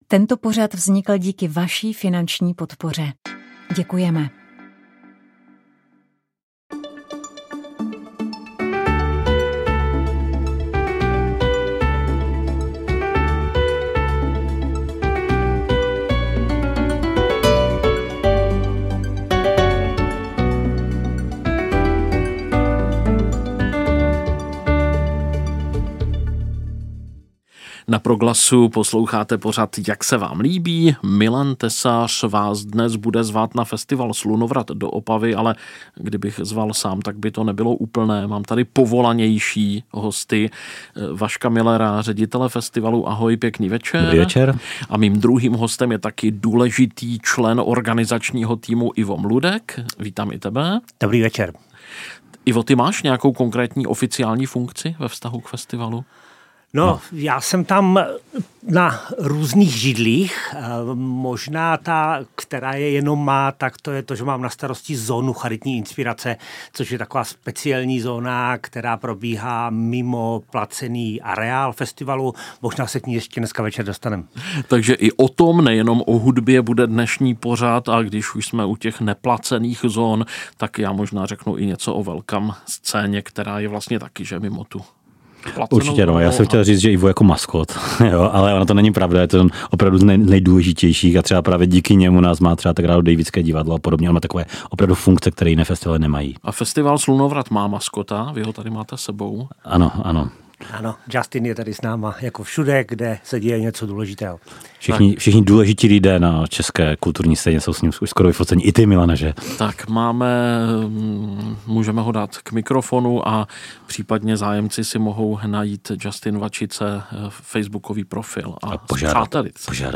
Jak se vám líbí – rozhovor s duem Ensoi - Radio Proglas